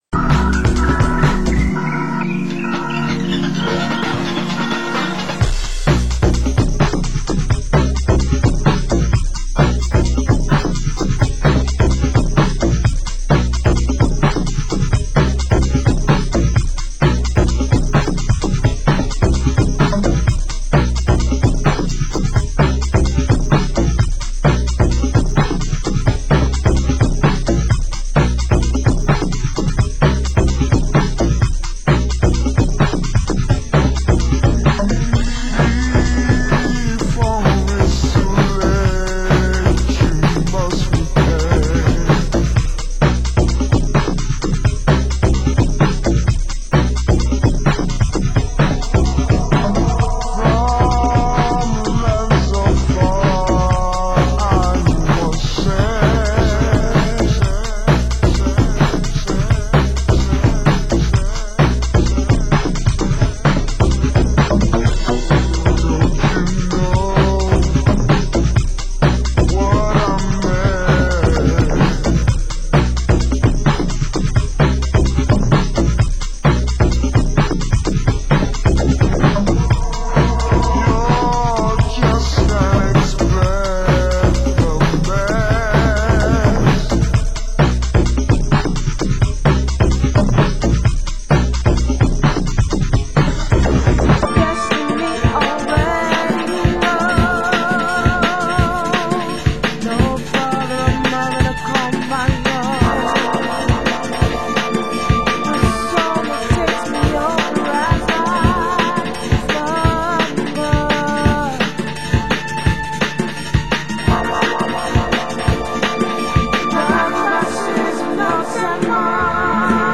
Genre: Drum & Bass